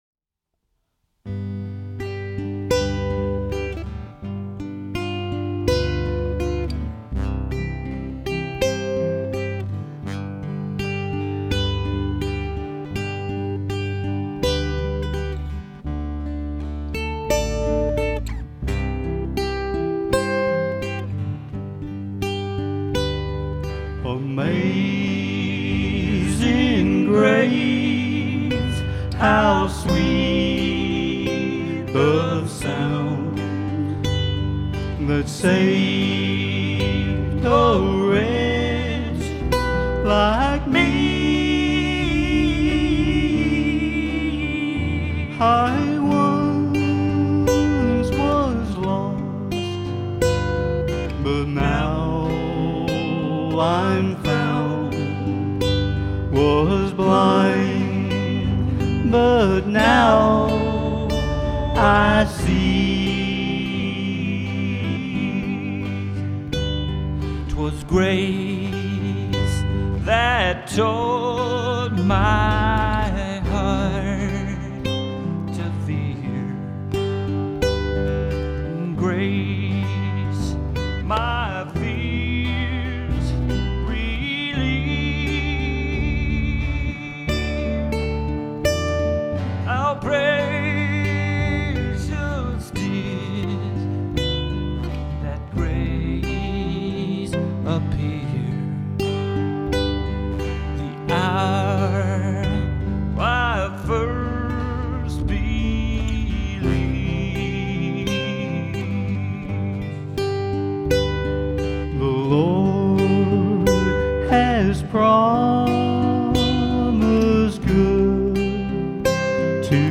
Traditional acoustic music, live and in person.